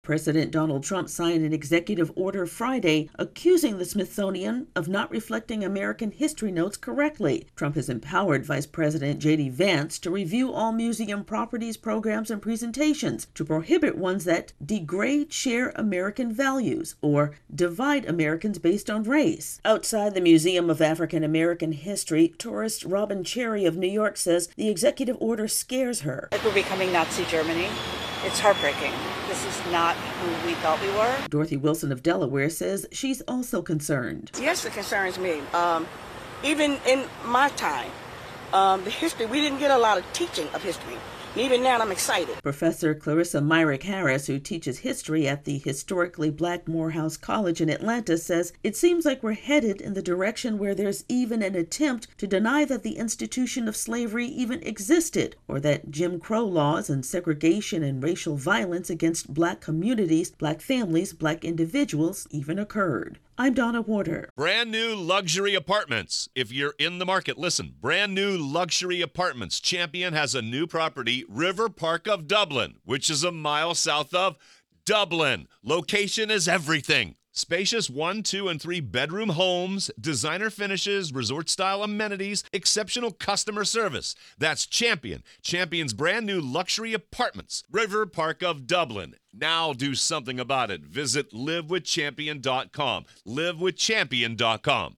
Latest Stories from The Associated Press